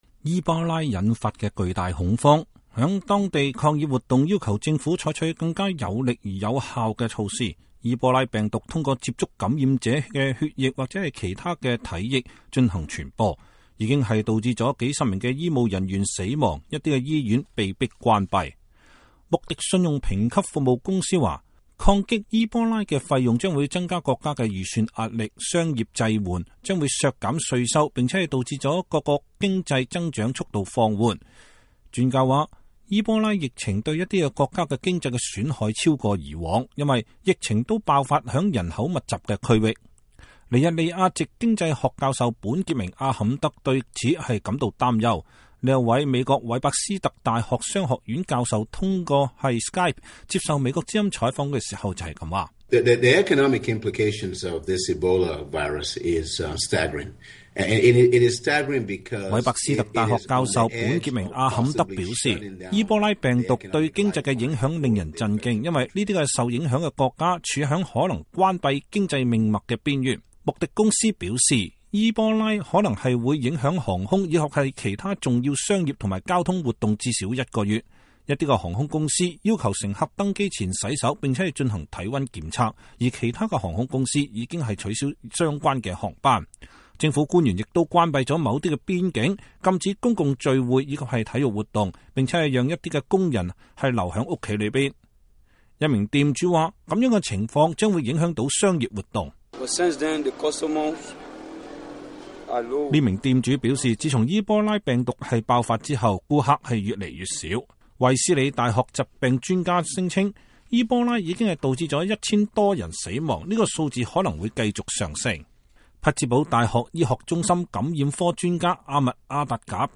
一位店主說這樣的情況影響了商業活動。